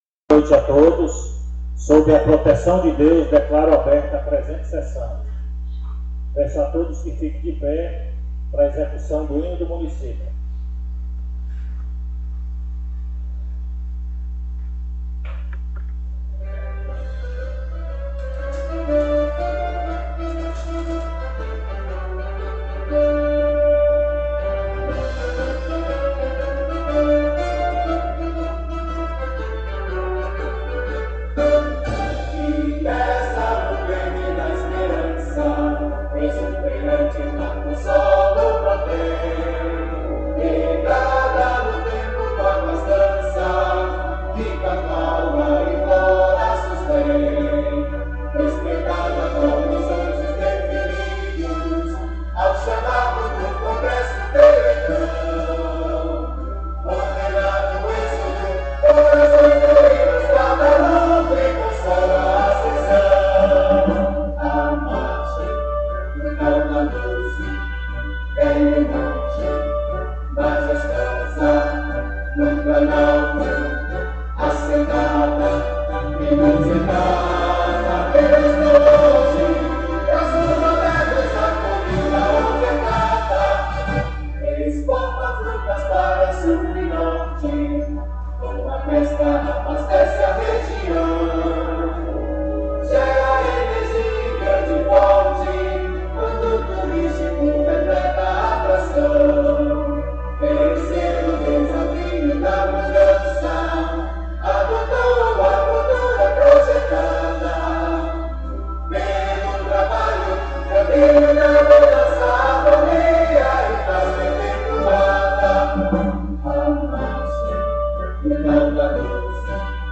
Mídias Sociais 5ª SESSÃO ORDINÁRIA PLENÁRIA 06 de abril de 2026 áudio de sessões anteriores Rádio Câmara A Sessão da Câmara de Vereadores ocorre na segunda-feira, a partir das 19:30h.